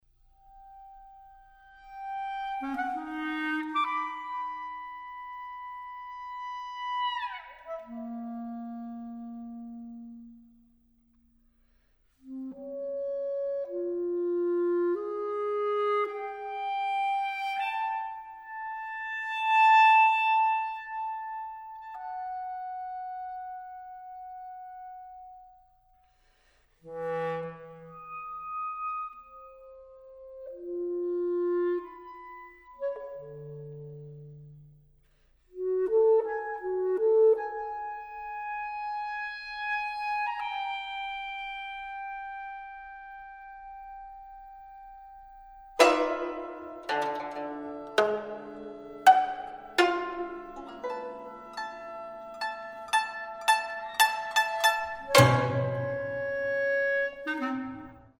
flute/piccolo
pipa
zheng
violin
cello